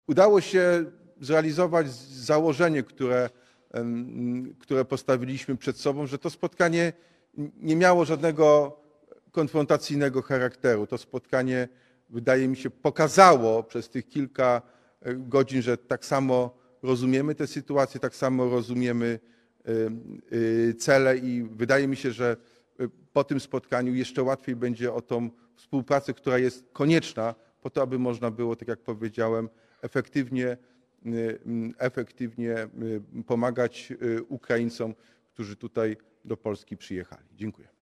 – Chciałbym bardzo serdecznie podziękować wszystkim za dzisiejszy udział, ale także za wczorajszą prace w podstolikach – dodał Jacek Sutryk.
Obradom Samorządowego Okrągłego Stołu przysłuchiwało się kilkaset osób, które zasiadły na widowni we wrocławskiej Hali Stulecia.